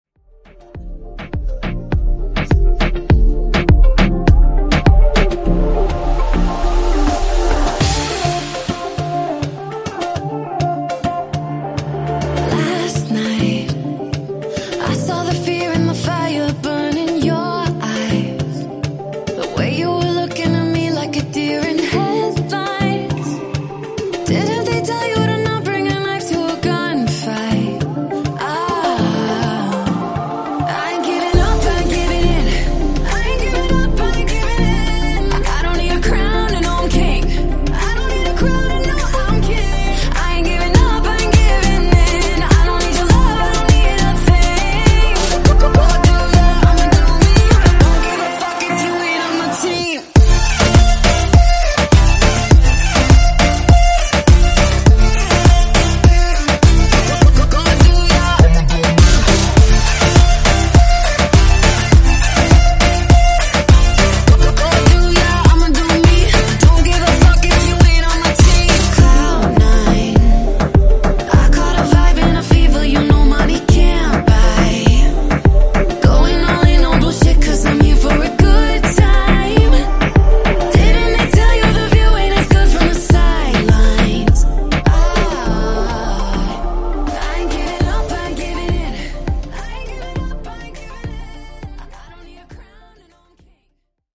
Genre: RE-DRUM
Clean BPM: 133 Time